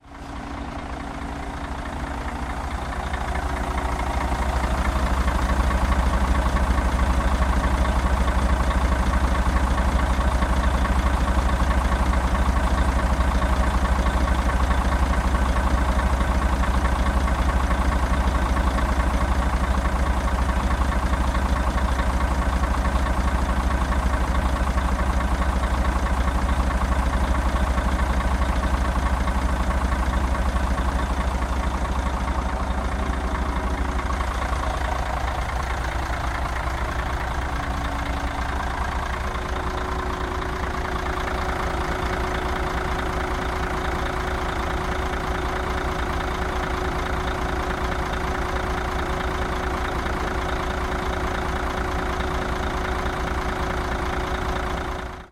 Bus Engine 002
描述：sennheiser mkh 416 sound device 744t
标签： vehicle bus engine
声道立体声